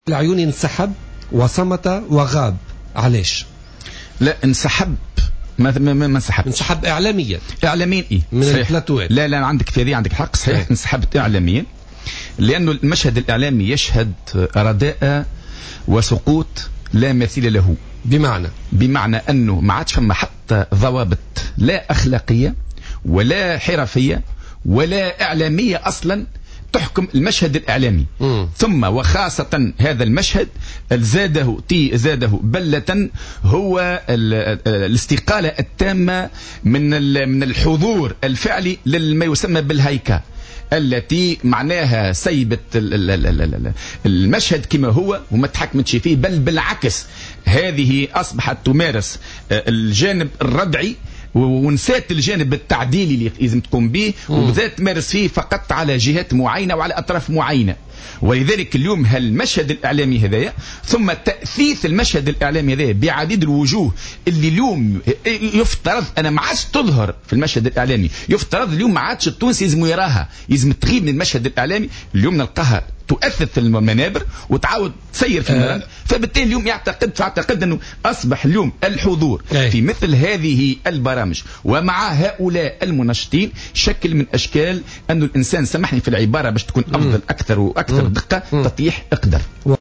مداخلة له اليوم الثلاثاء على "جوهرة أف أم"